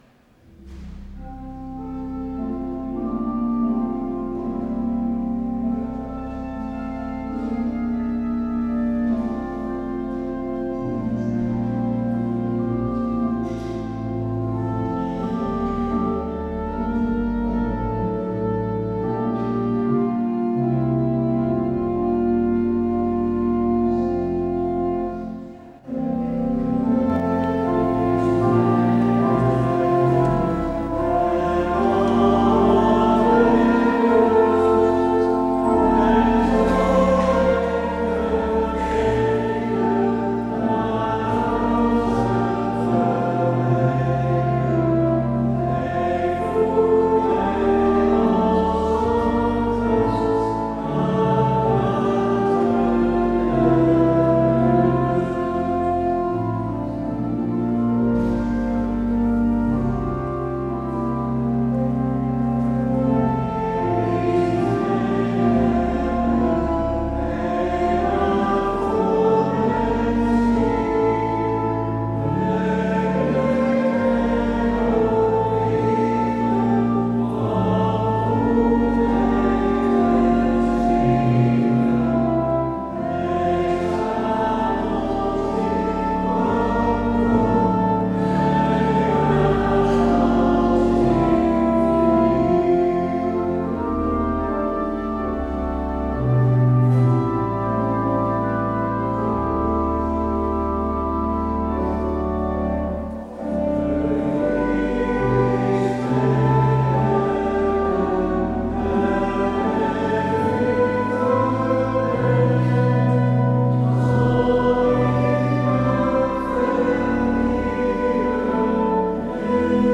Het openingslied is: Psalm 139: 1, 14.